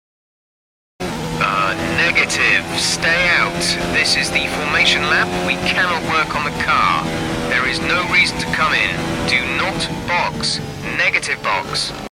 *tire pops*